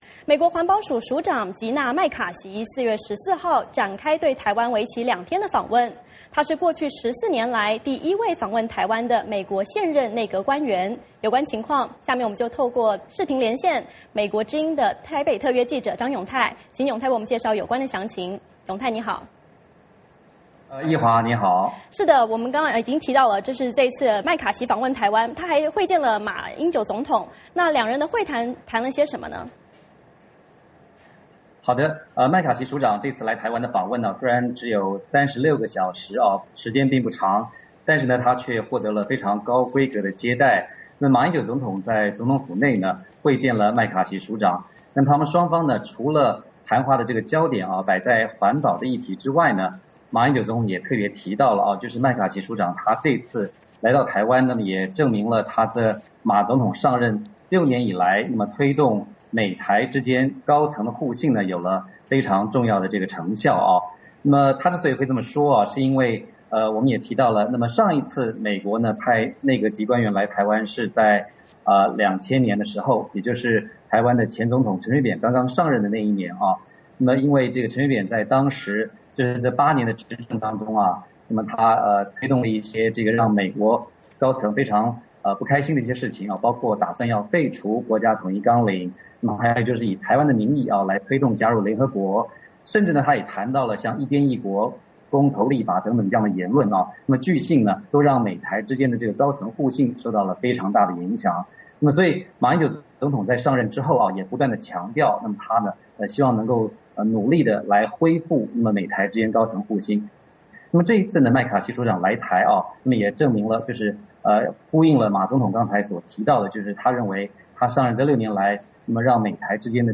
VOA连线：美国15年来首位现任内阁级官员访台